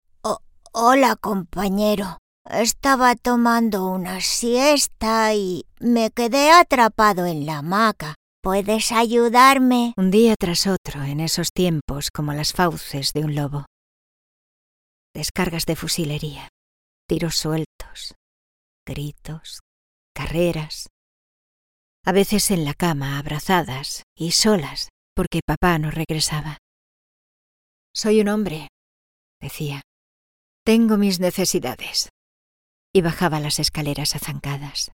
Female
Bright, Character, Children, Natural, Warm, Versatile, Confident
Andalucía (native)
People say my voice sounds very clear, warm, trustworthy and expressive and that it can also sound fun, educational and informative…
Microphone: Neumann TLM 103, Rode NT1A & Blue Microphones Yeti USB